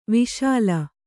♪ viśala